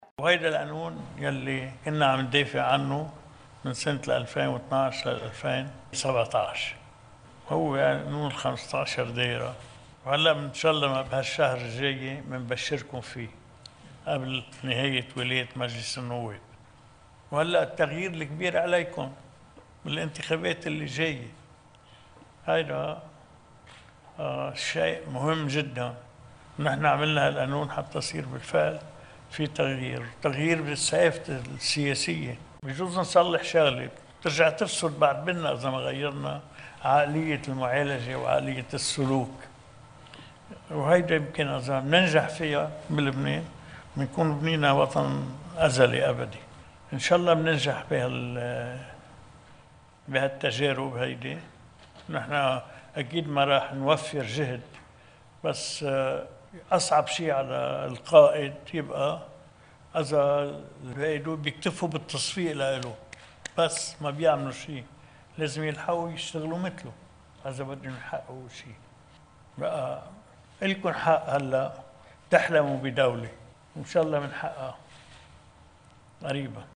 مقتطف من حديث الرئيس عون أمام وفد من الجامعة الهاشمية وفروعها في لبنان: